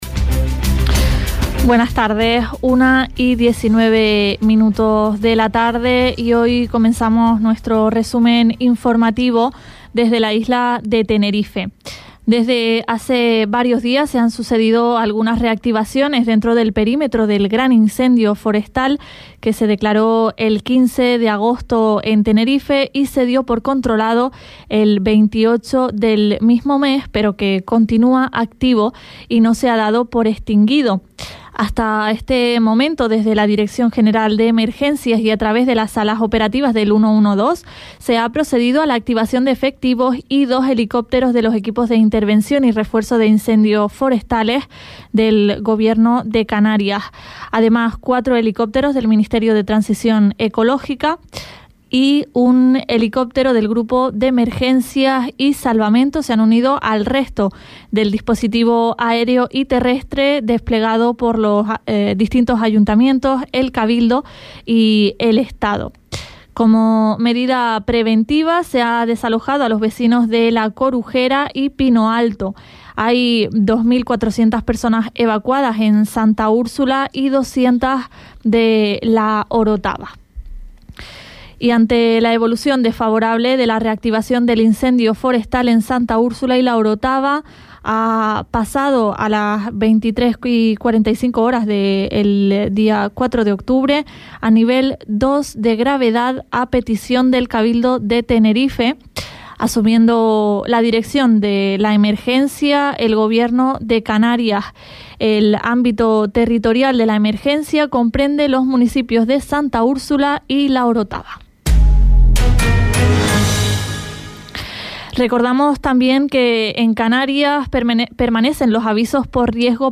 Servicios Informativos